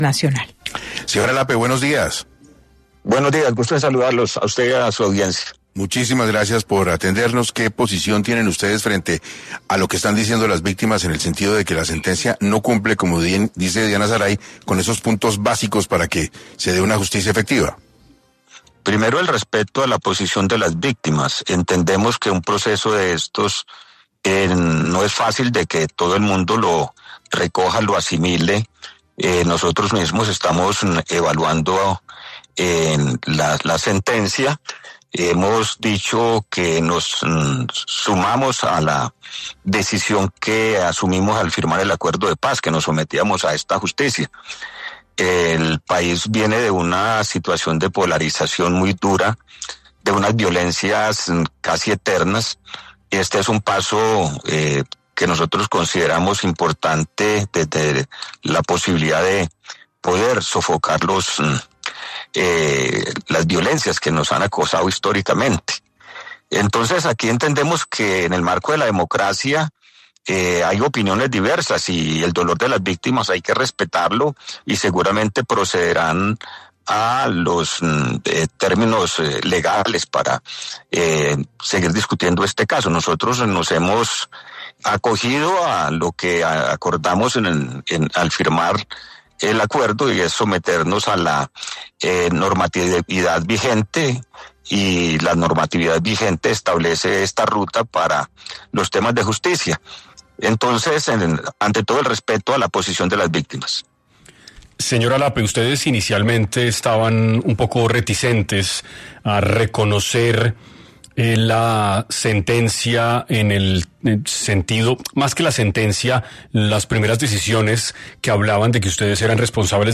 El vocero del Antiguo Secretariado de las Farc habló en 6AM de Caracol Radio sobre la sentencia de la JEP y la inconformidad de parte de las víctimas.
Frente a esto, el vocero del Antiguo Secretariado de las Farc, Pastor Alape, habló el miércoles 17 de septiembre en los micrófonos de 6AM de Caracol Radio y se refirió a la reciente sentencia, a las críticas de las víctimas y las medidas que deberán cumplir los exmiembros del antiguo Secretariado.